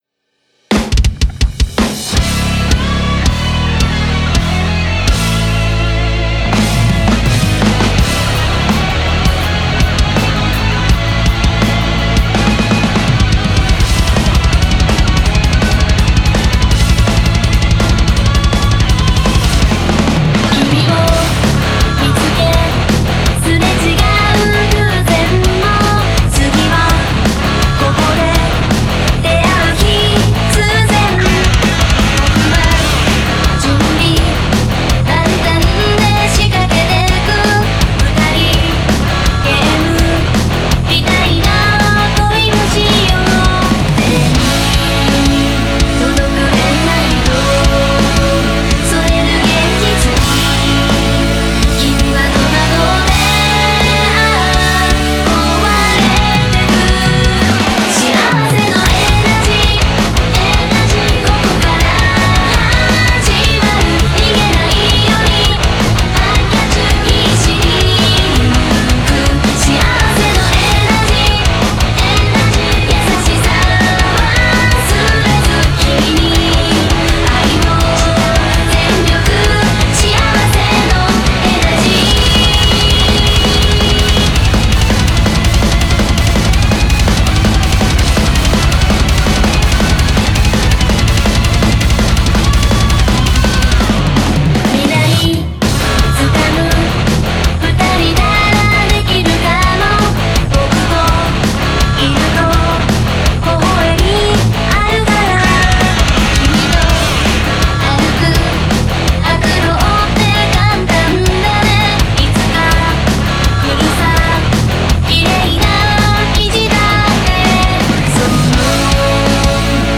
Genre: METAL
BPM : 165